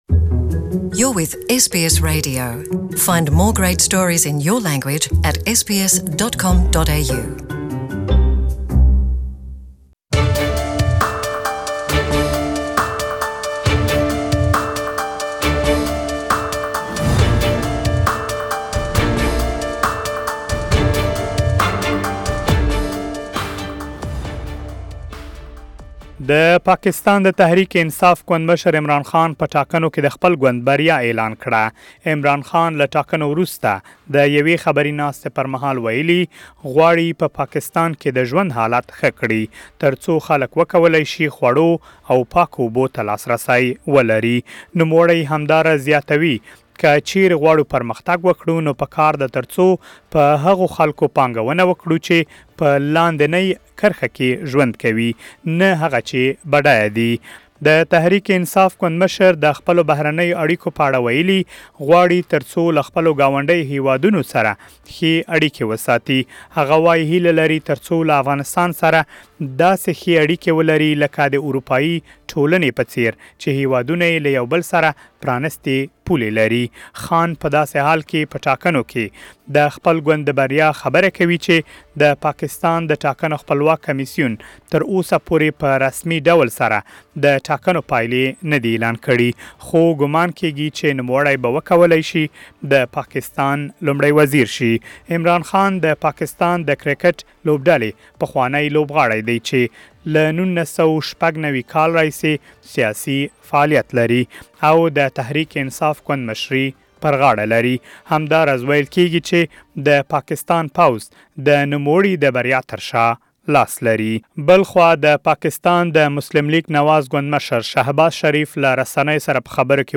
For more details, Please listen to the full report in Pashto.